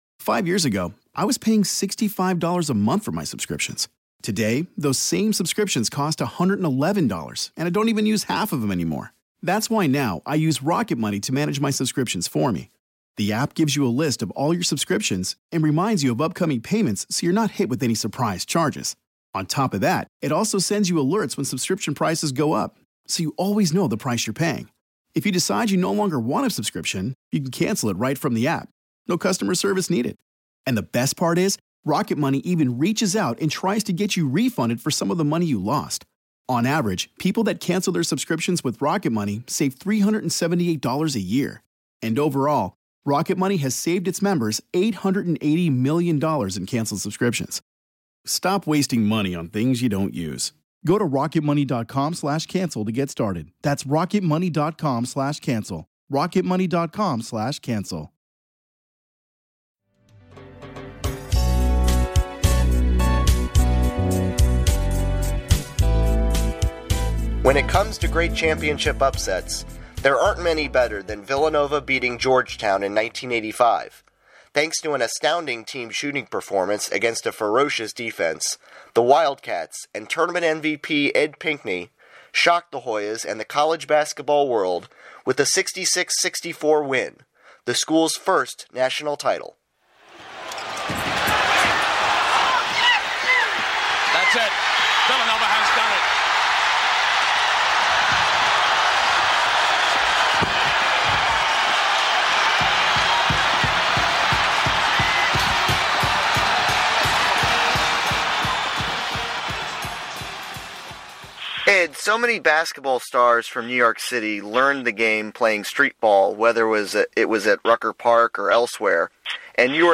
Audio clips courtesy of CBS Sports